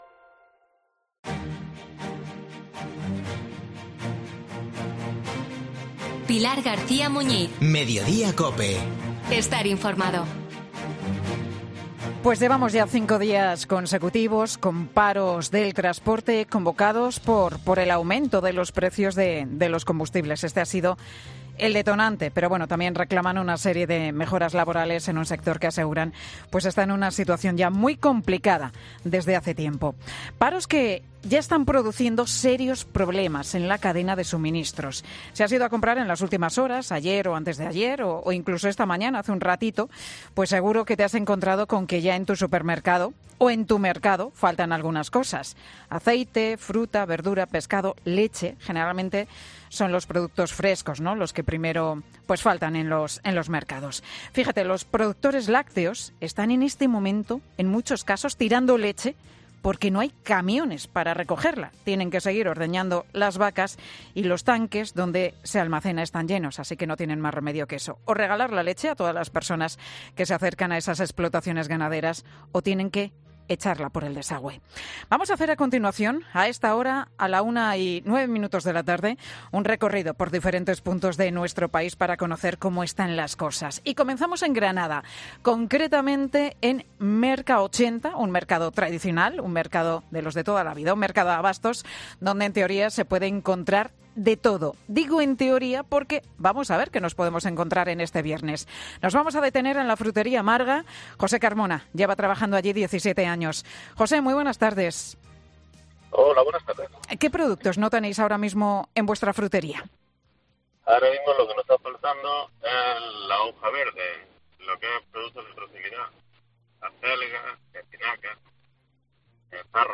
Mediodía COPE se acerca hasta tres mercados con serias limitaciones de productos
Mediodía COPE ha hecho un recorrido por diferentes puntos de nuestro país para conocer cómo están las cosas.